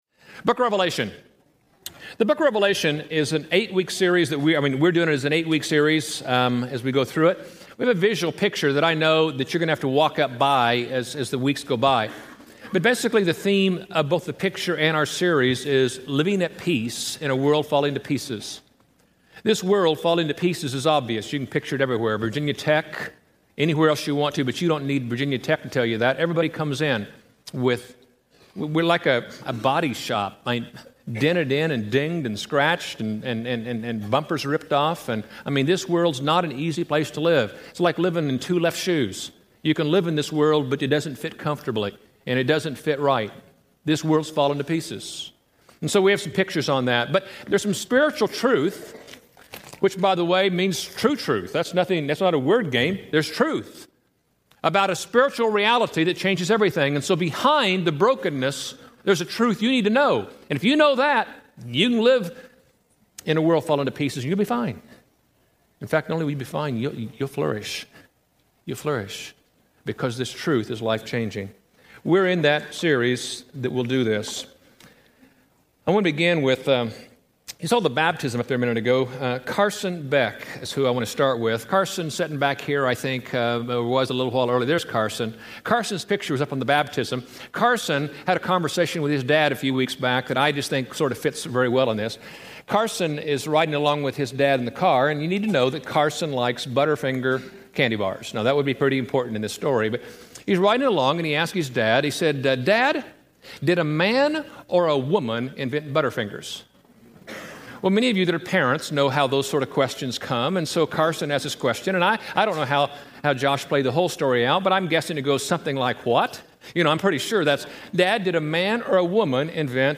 Hey Church, You’ve Got Mail Preached at College Heights Christian Church April 22, 2007 Series: Living at Peace in a World Falling to Pieces Scripture: Revelation 2-3 Audio